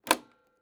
Switch (4).wav